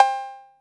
TR 808 Cowbell.wav